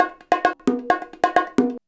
133BONG05.wav